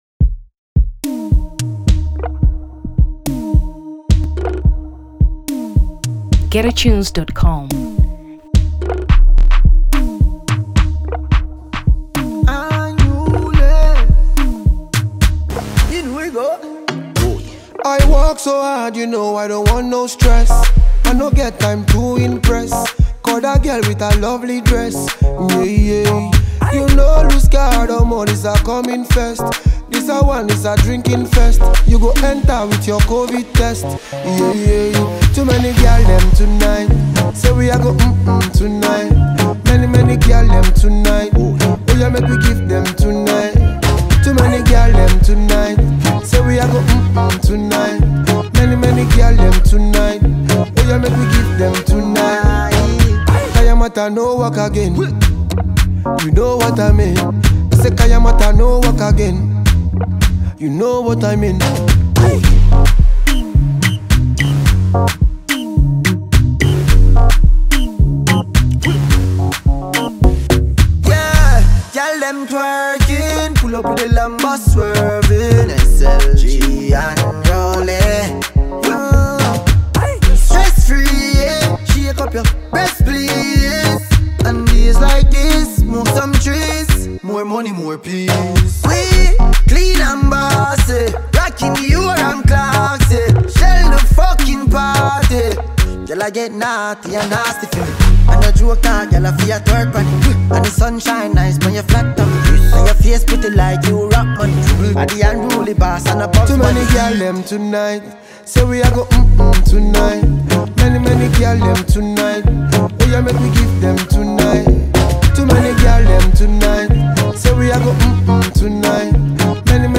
Afrobeat 2023 Nigeria